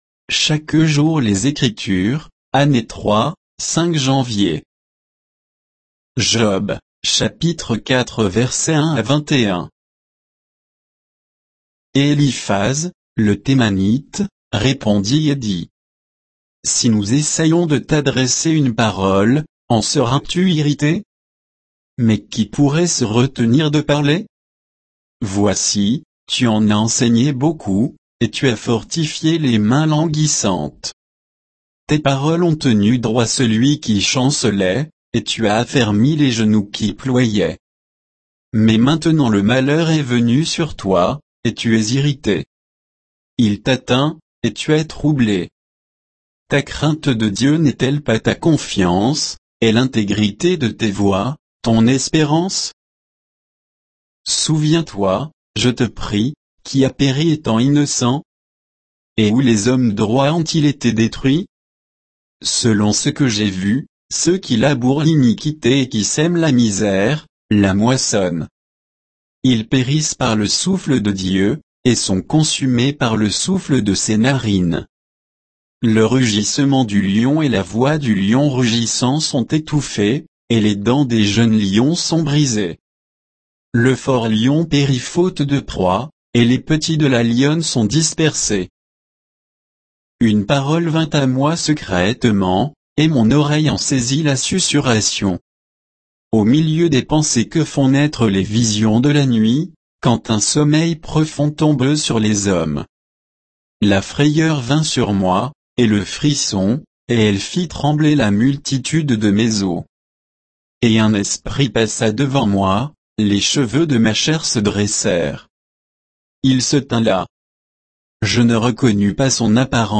Méditation quoditienne de Chaque jour les Écritures sur Job 4